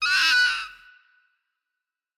assets / minecraft / sounds / mob / fox / screech1.ogg
screech1.ogg